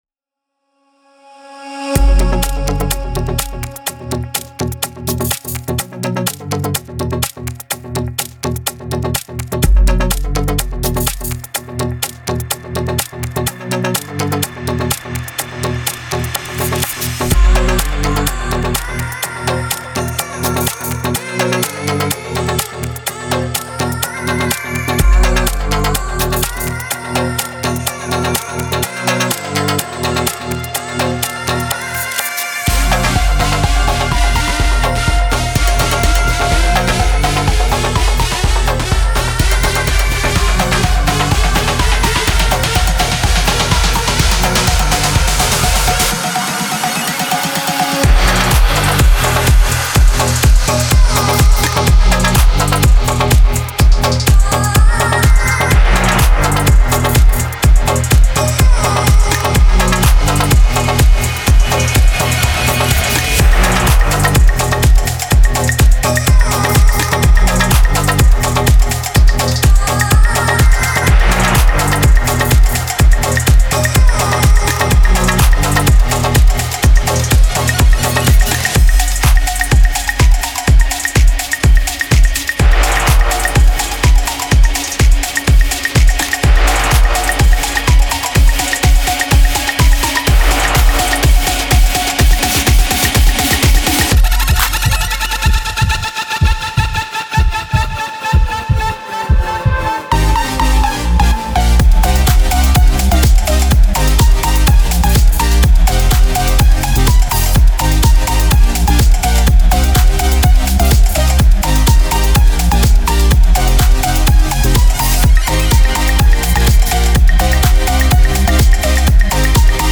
Genre: Electro House.